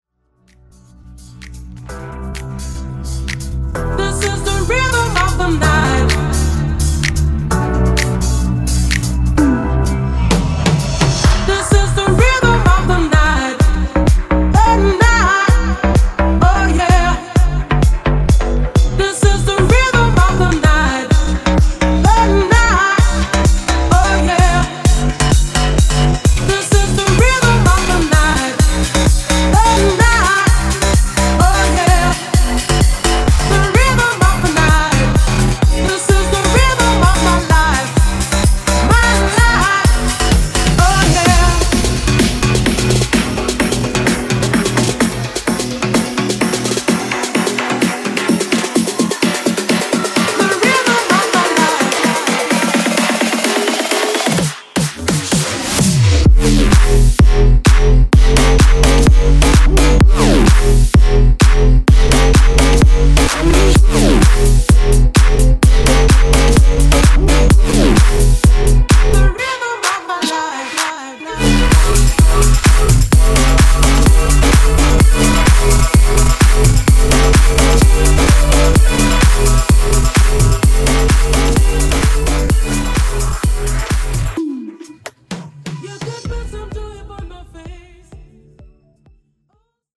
Bigroom Edit)Date Added